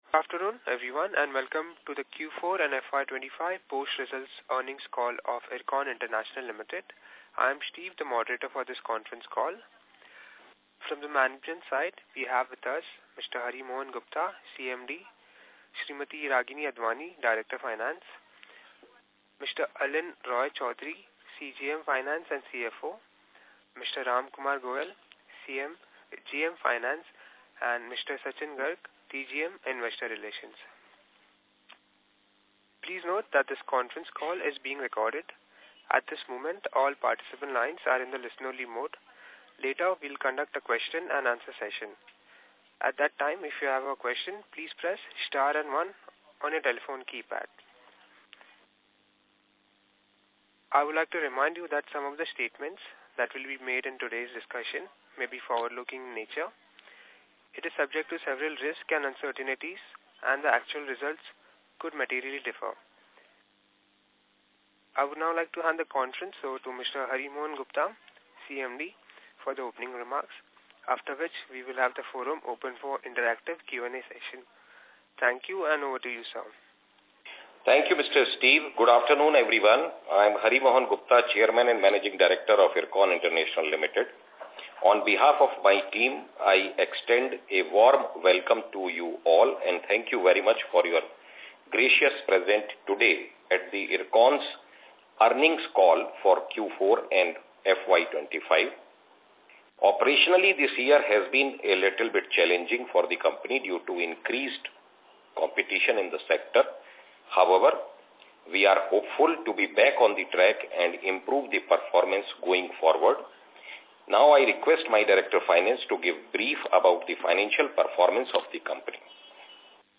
Audio for the concall held on 22nd May, 2024.